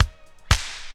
35DR.BREAK.wav